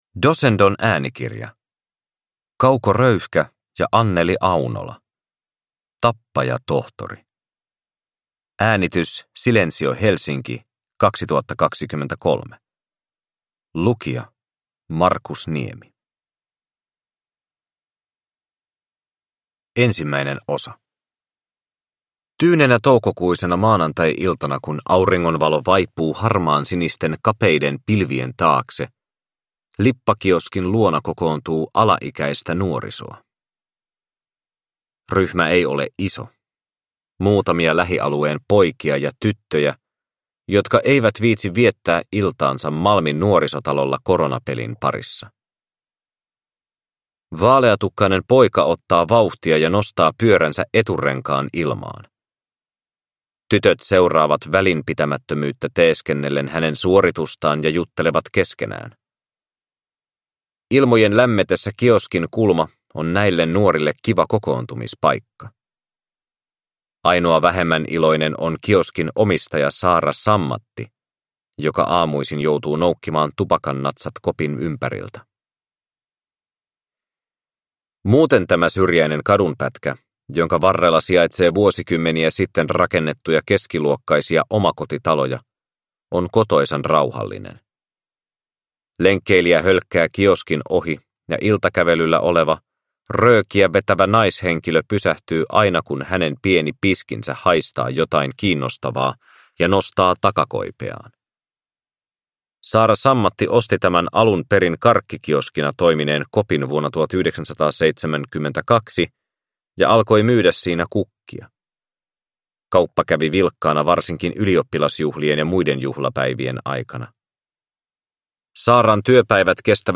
Tappajatohtori – Ljudbok – Laddas ner